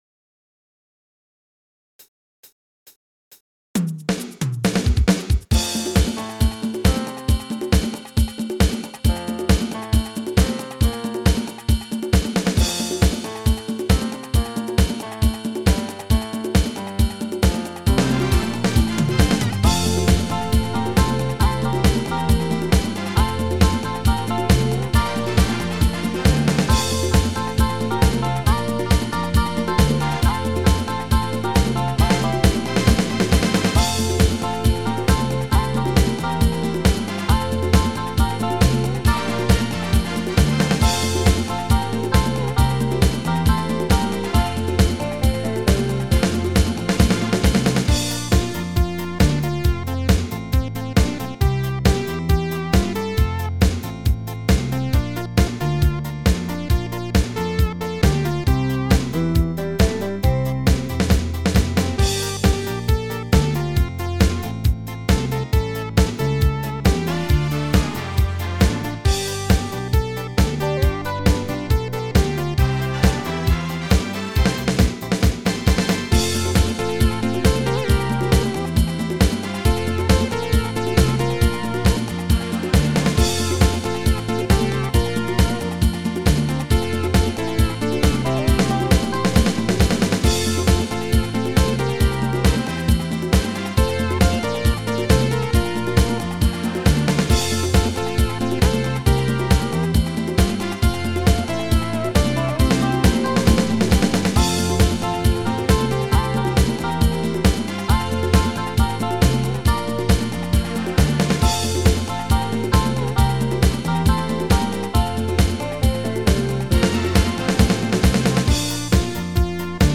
PRO MIDI Karaoke INSTRUMENTAL VERSION
Alpenrock